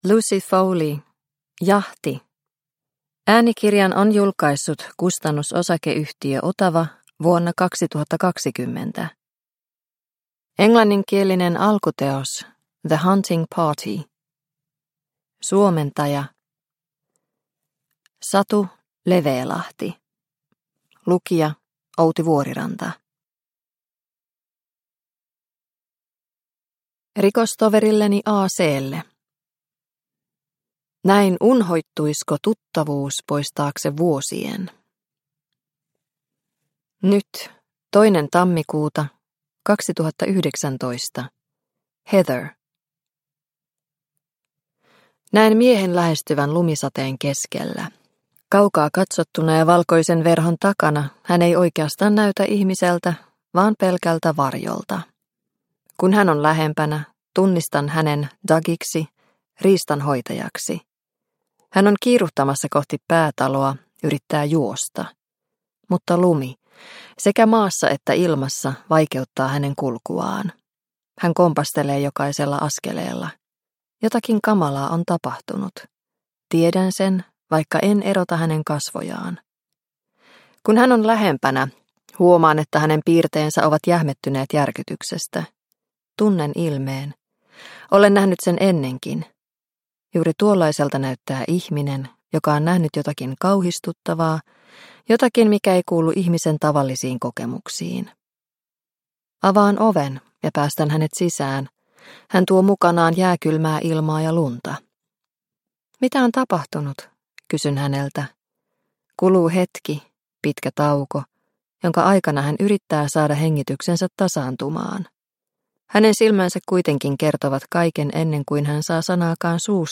Jahti – Ljudbok – Laddas ner